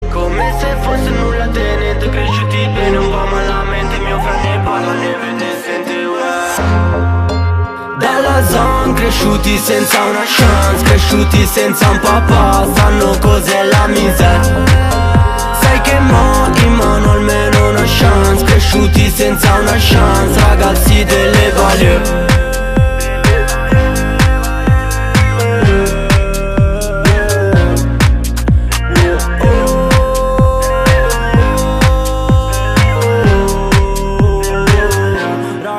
Categoria Hip Hop